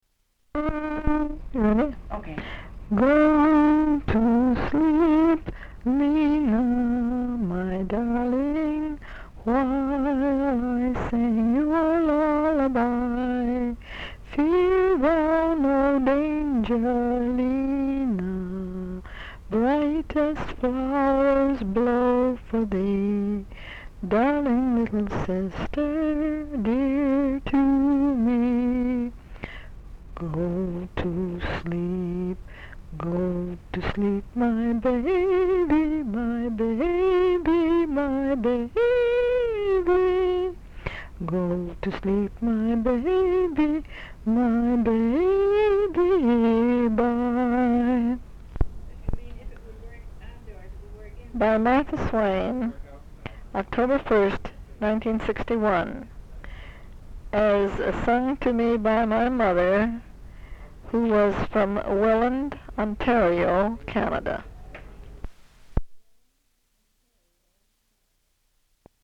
sound tape reel (analog)
Brattleboro, Vermont